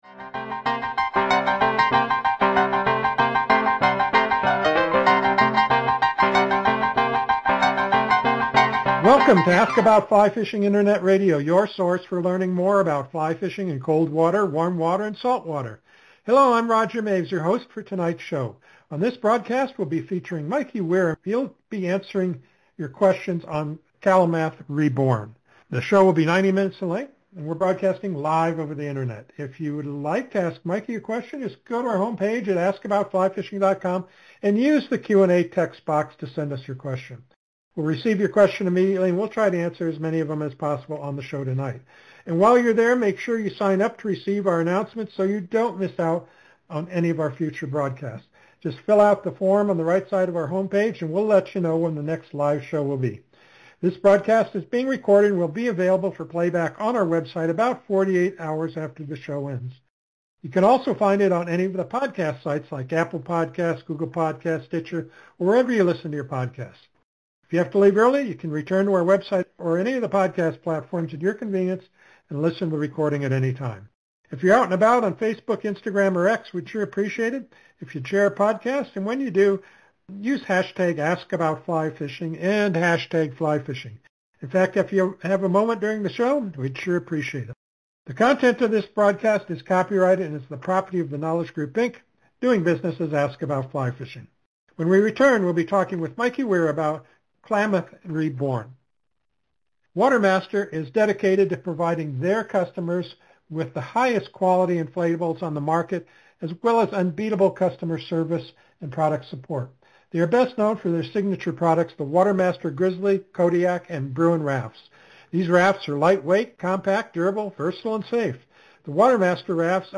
Just a few of the questions asked and answered during the interview: